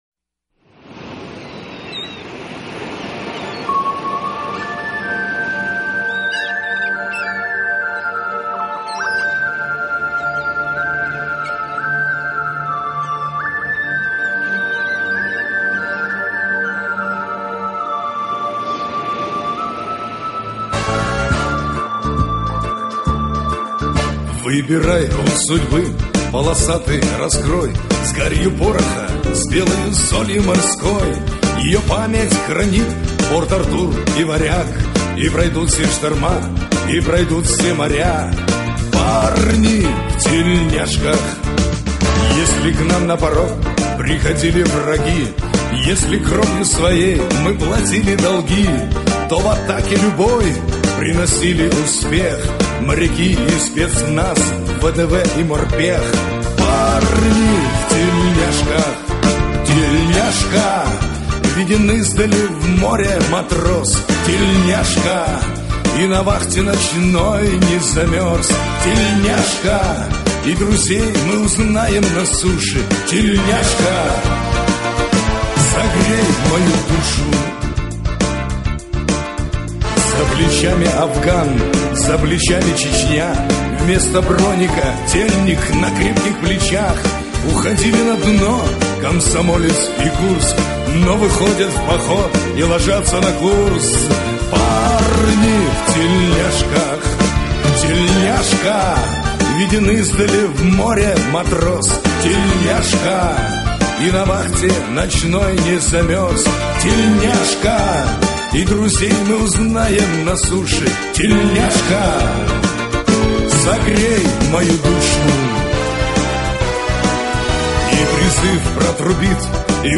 Главная / Computer & mobile / Мелодии / Марши